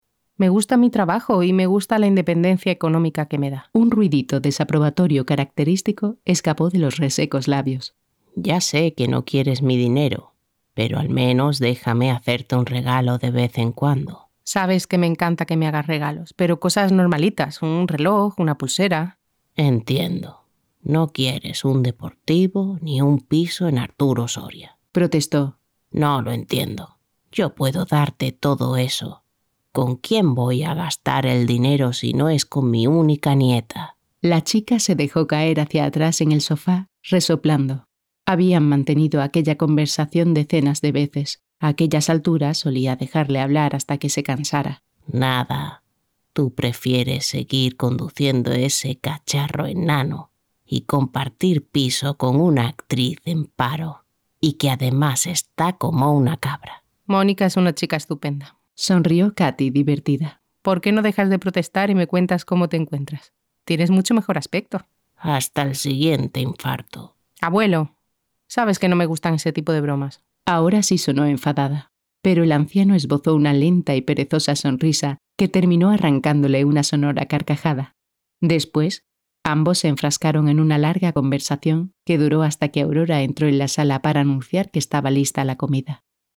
Audiolibro ¿Quién Eres? (Who Are You?)